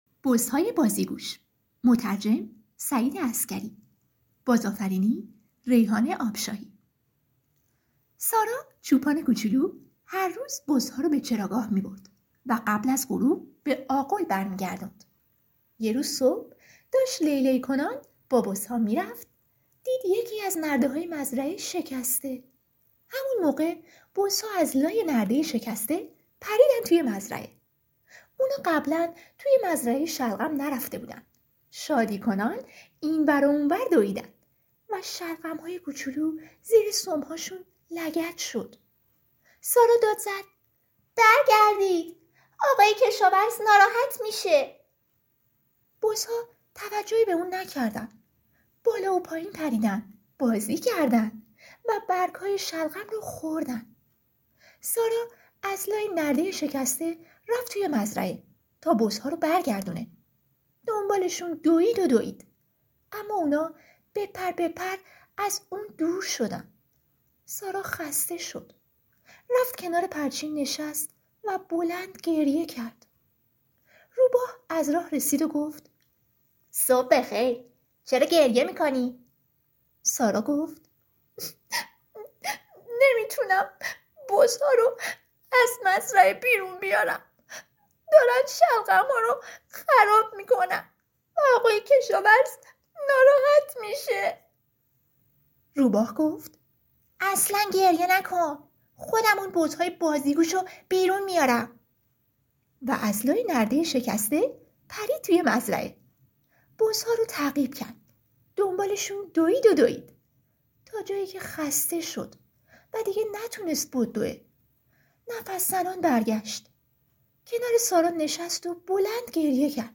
قصه-صوتی-بزهای-بازیگوش.mp3